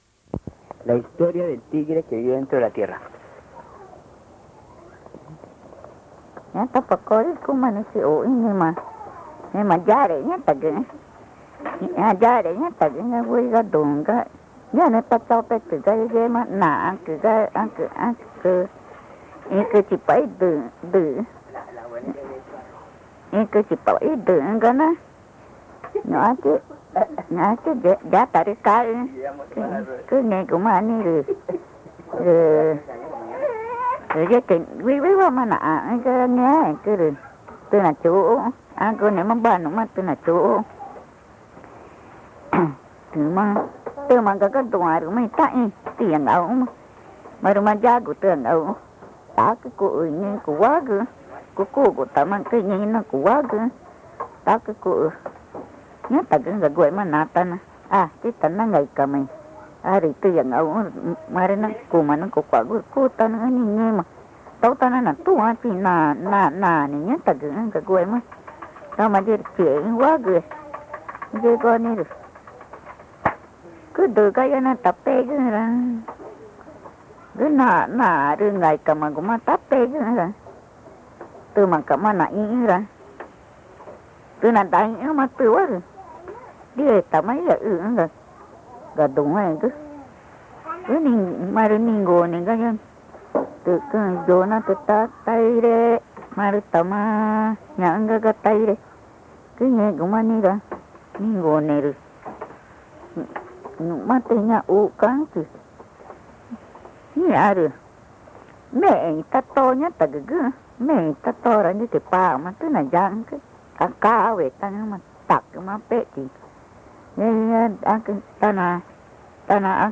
Boyahuazú, Amazonas (Colombia)
introduce la narración en Español.